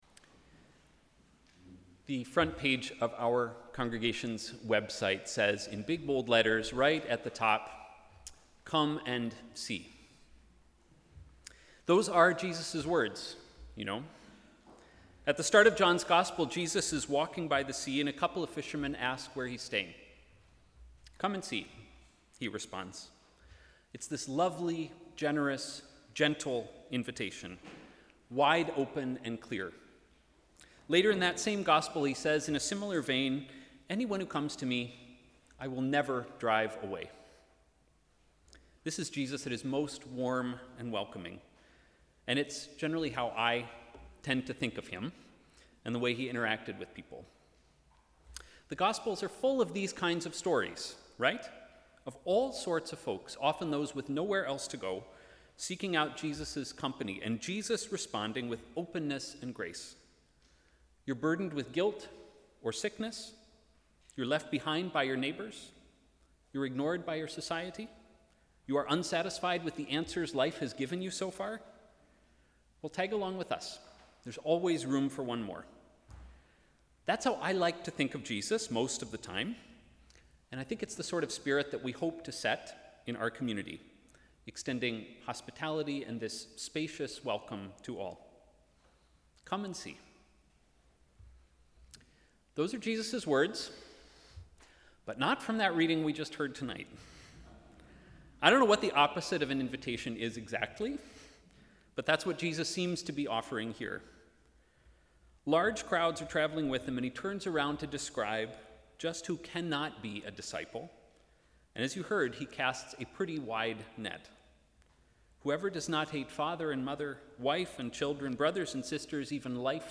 Sermon: ‘A costly following’